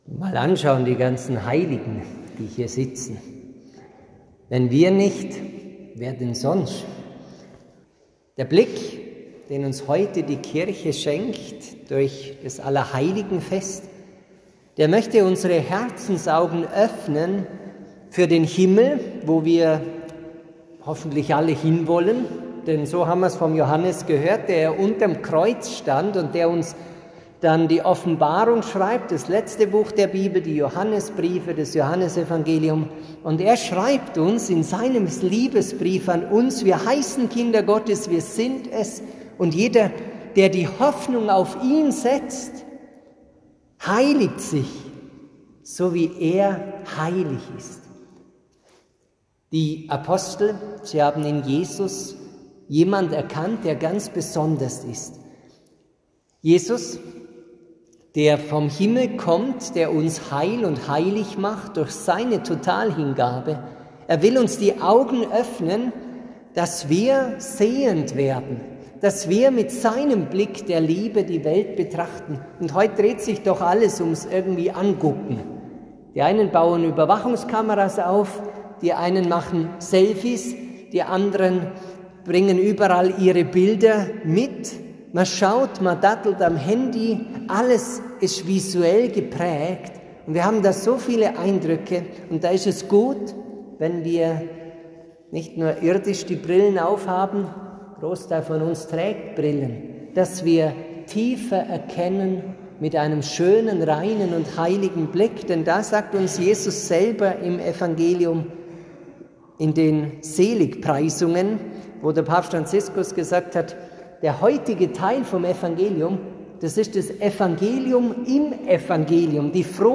Predigt-Allerheiligen-2025.mp3